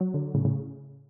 call_declined-C6gHsCGH.ogg